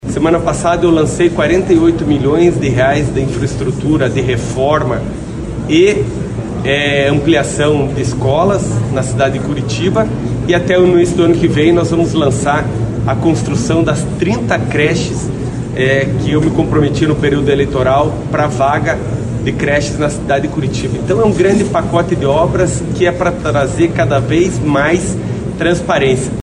SONORA-PIMENTEL-OBRAS-2-GJ.mp3